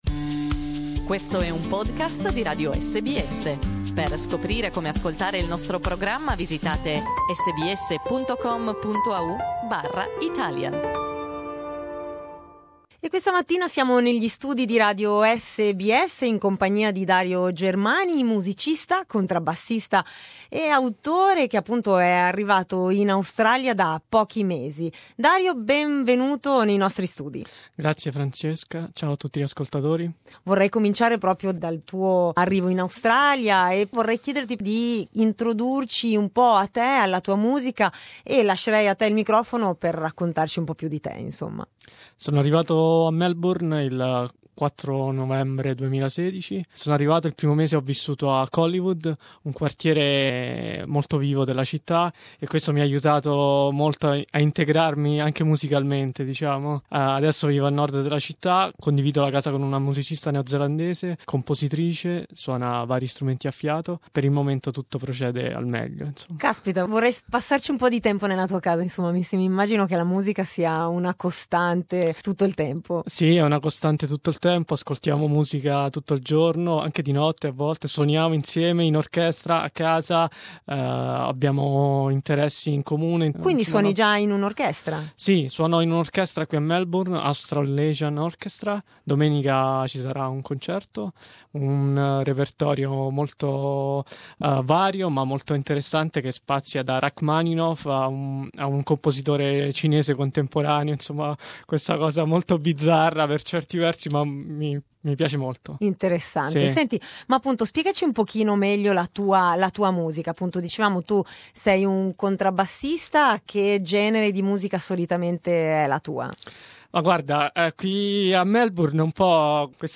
He visited us in our studios to talk about the life of a musician in Australia and talk about his music, that mixes jazz with electronic tunes.